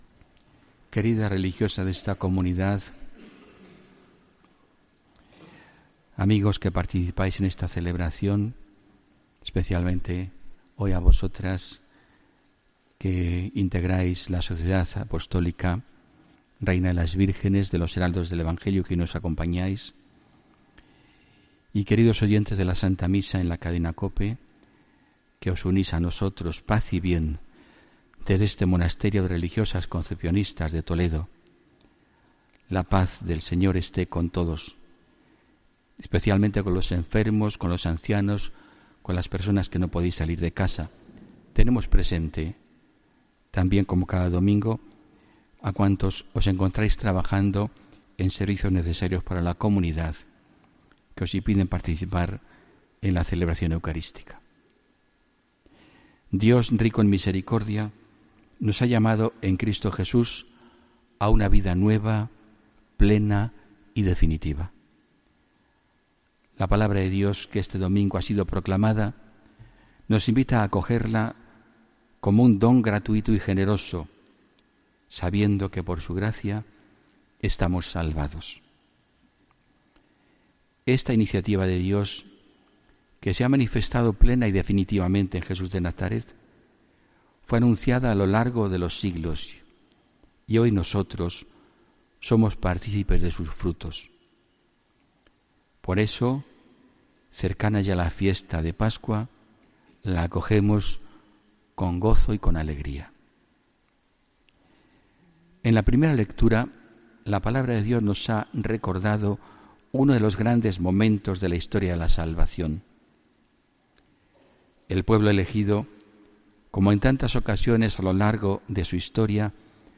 HOMILÍA 11 MARZO 2018